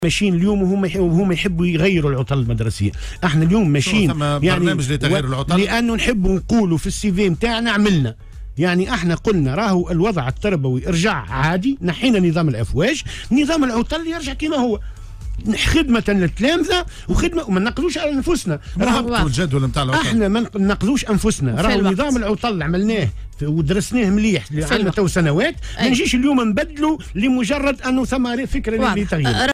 وقال في مداخلة له اليوم على "الجوهرة أف أم"، إنه لا جدوى من ذلك خاصة بعد الاستغناء عن نظام الأفواج وعودة الدروس إلى نسقها والعادي.